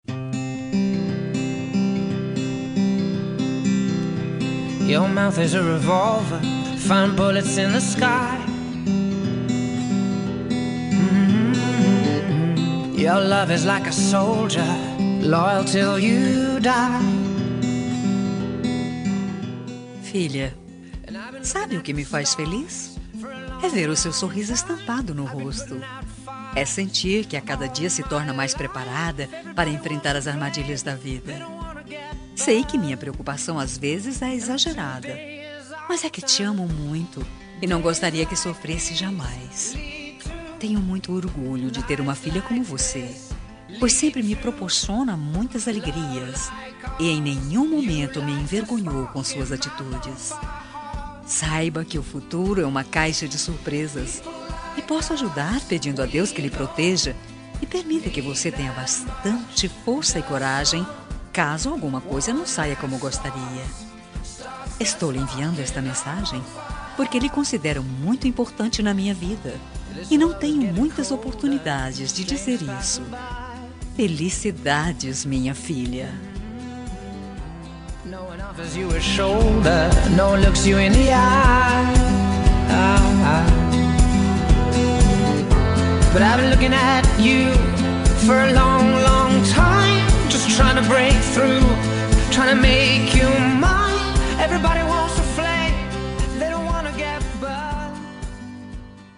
Homenagem para Filha – Voz Feminina – Cód: 8135